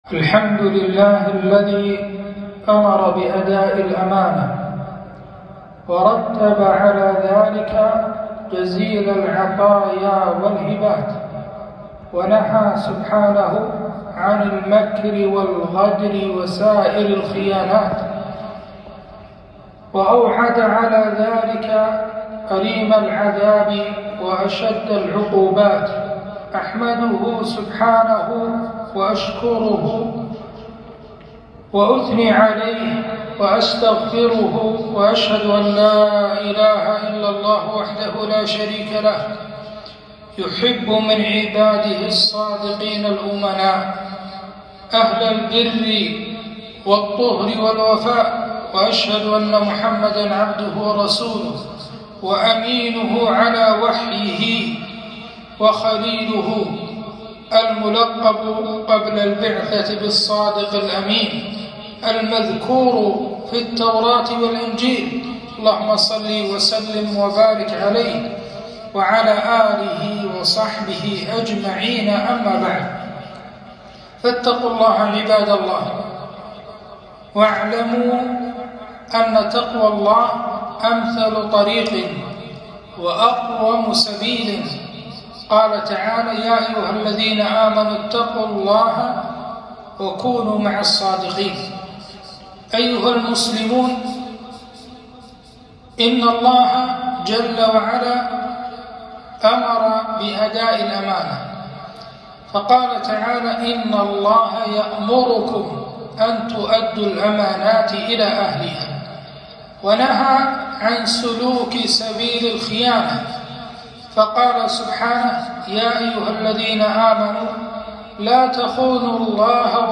خطبة - أداء الأمانة